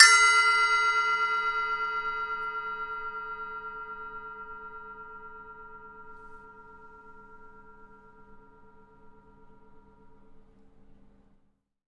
铃铛镲片 " 铃铛2
描述：7"黑色金属钟形钹用RODE NT1A录制到Maudio FastTrack Ultra 8R in Reason 6.5 DAW.